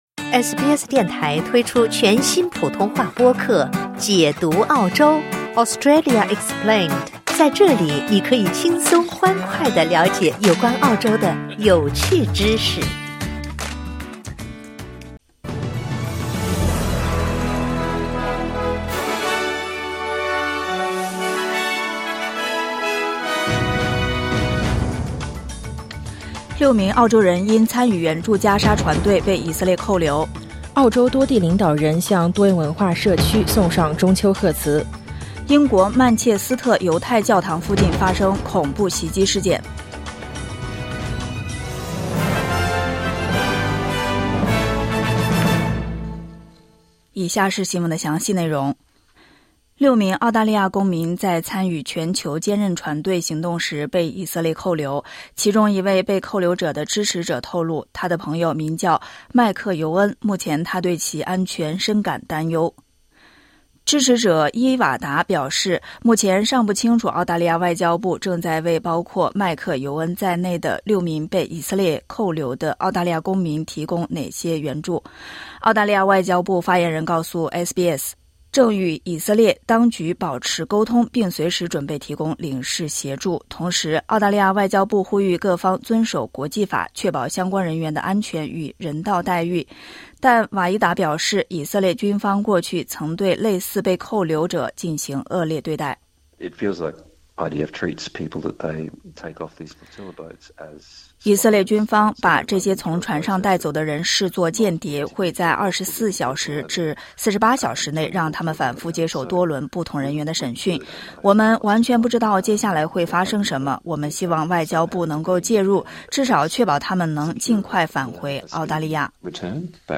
SBS Mandarin morning news Source: Getty / Getty Images